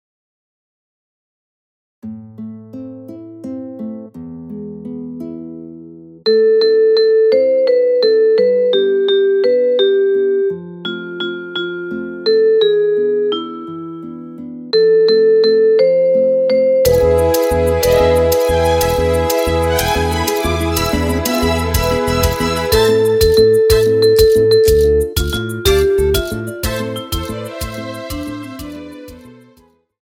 Recueil pour Flûte traversière - Flûte Traversière